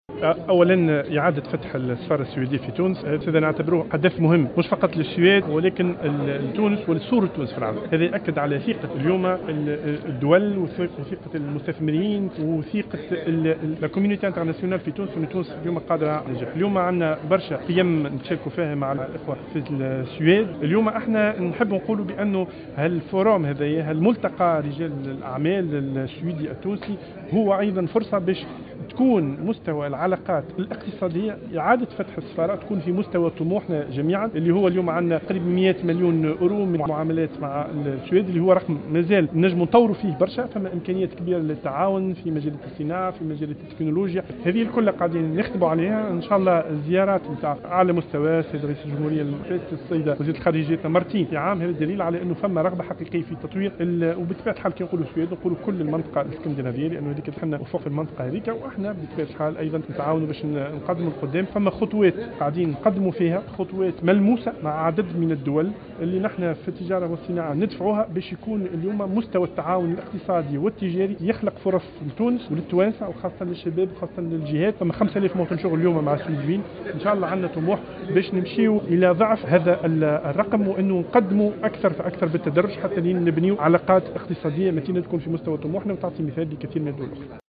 أكد وزير الصناعة والتجارة التونسي زياد العذاري في تصريح اعلامي اليوم الأربعاء 26 أكتوبر 2016 على هامش افتتاح أشغال منتدى الأعمال التونسي السويدي أن إعطاء اشارة اعادة فتح السفارة السويدية بتونس بعد غلق دام 15 عاما يعد خطوة مهمة في العلاقات الاقتصادية بين البلدين .